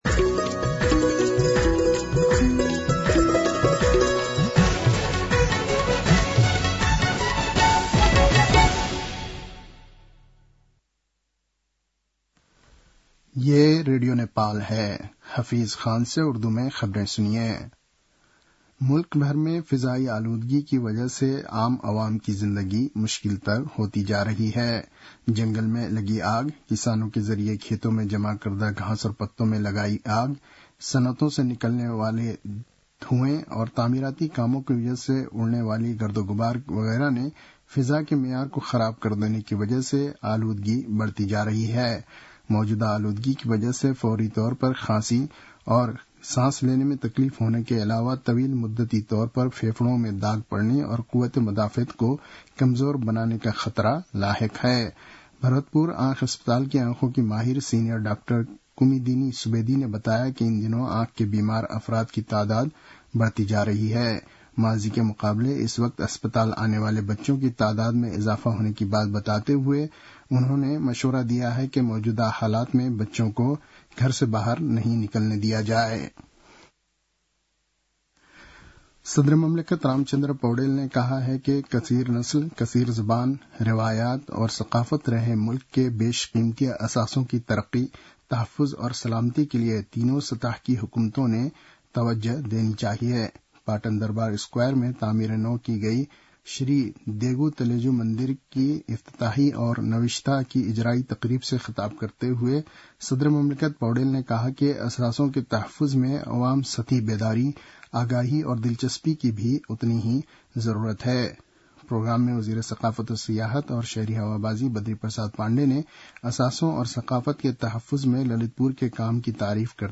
उर्दु भाषामा समाचार : २४ चैत , २०८१
Urdu-News.mp3